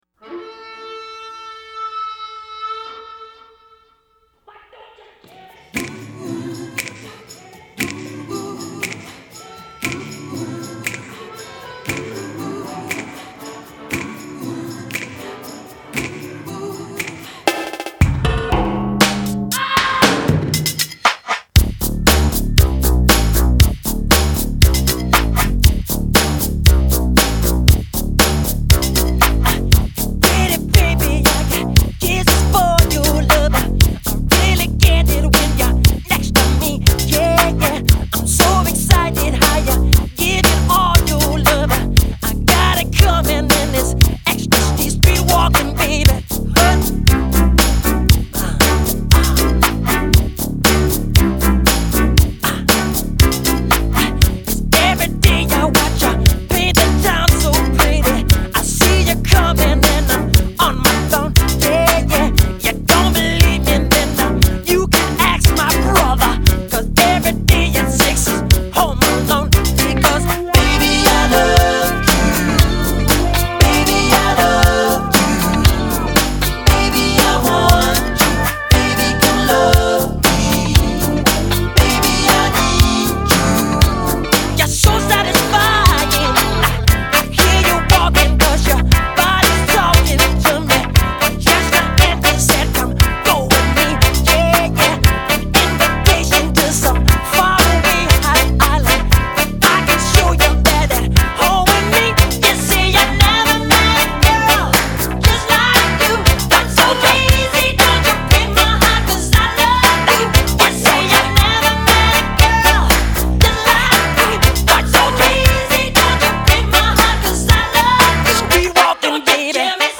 Жанр: Pop-Rock, Soul Pop, downtempo, Funk